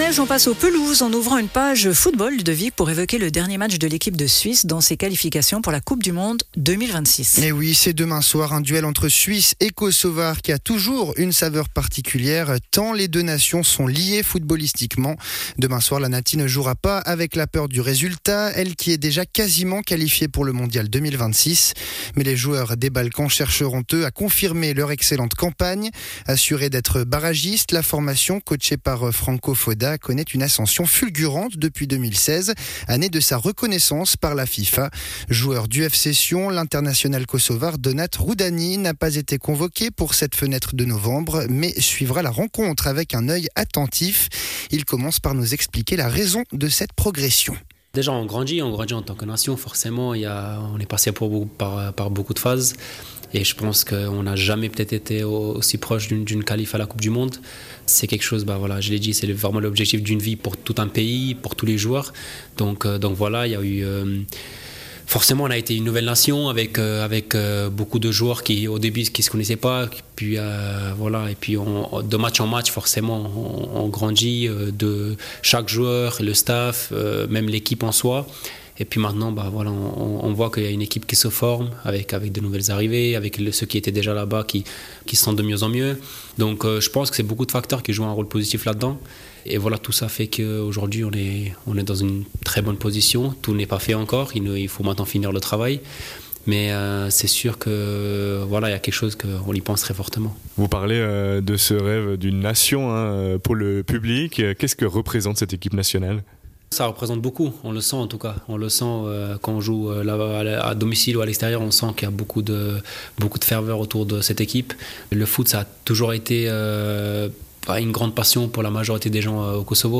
Intervenant(e) : Donat Rrudhani, joueur kosovar du FC Sion